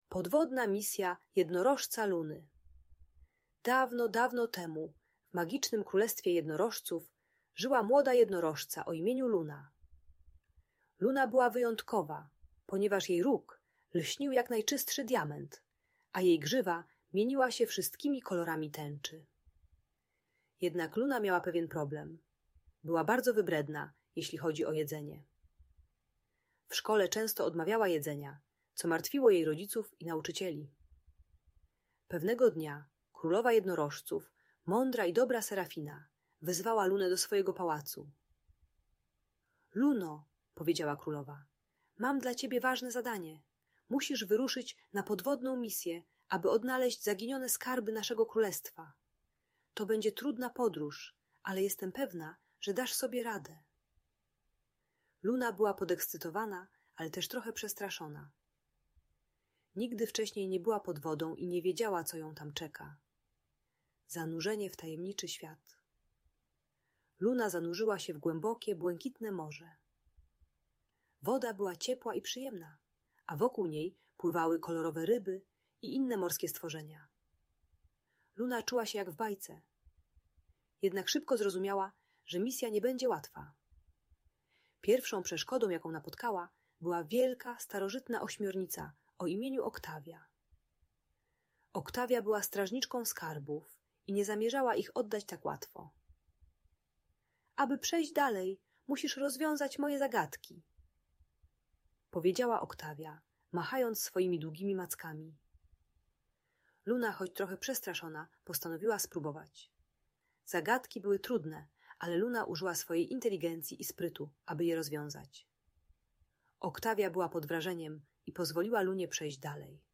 Podwodna Misja Jednorożca Luny - Audiobajka